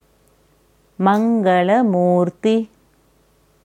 Mangalamurti मङ्गलमूर्ति maṅgala-mūrti Aussprache
Hier kannst du hören, wie das Sanskritwort Mangalamurti, मङ्गलमूर्ति, maṅgala-mūrti ausgesprochen wird: